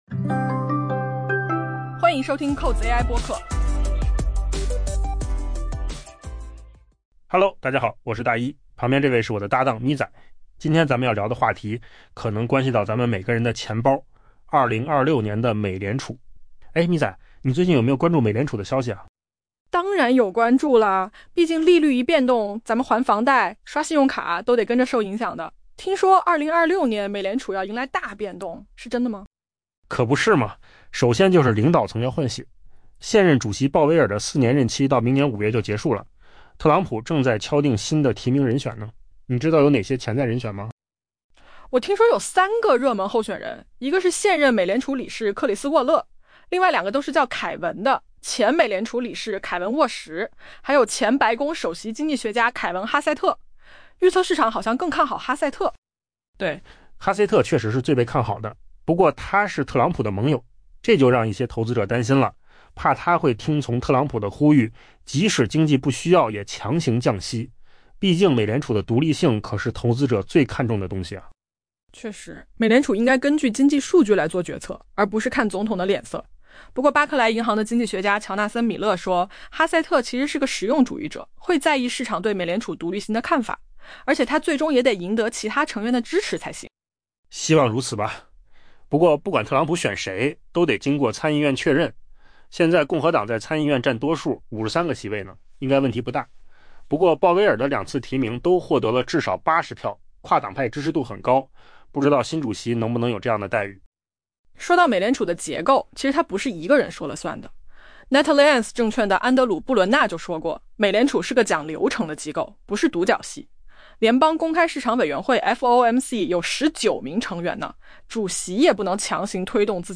AI 播客：换个方式听新闻 下载 mp3 音频由扣子空间生成 美联储即将步入棘手的 2026 年。